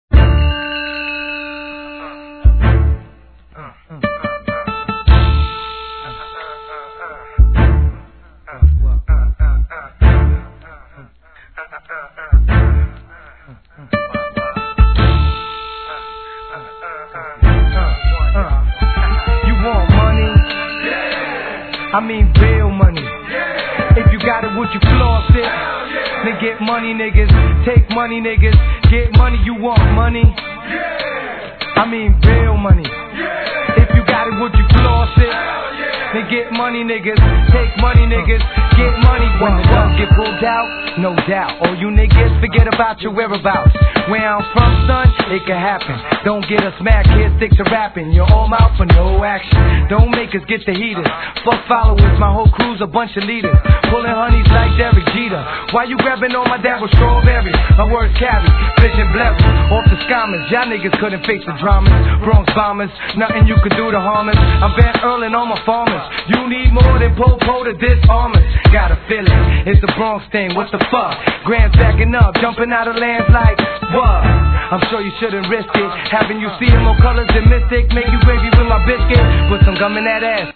HIP HOP/R&B
コンセプト通りのハードコアな内容!!